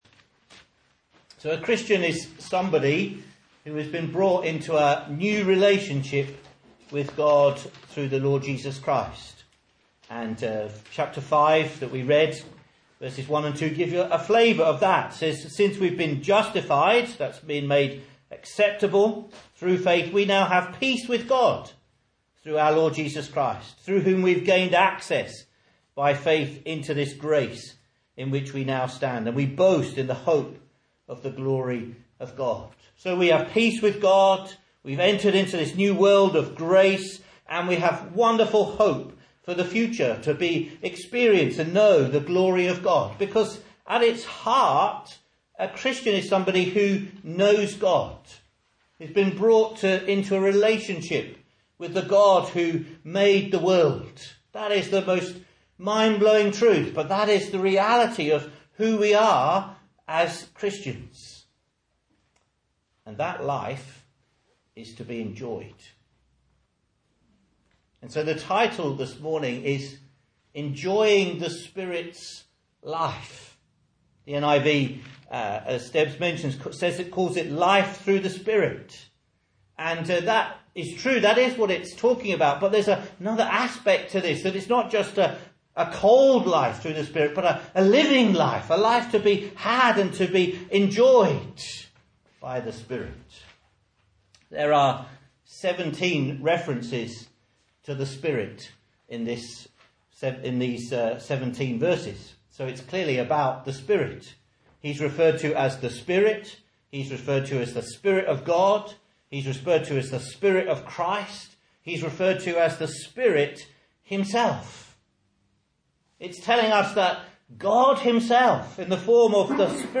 Message Scripture: Romans 8:1-17 | Listen